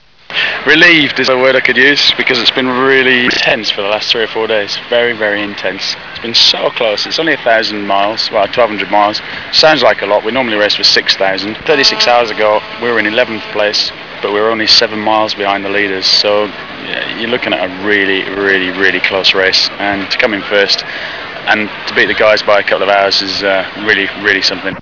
The following clips were recorded during the race.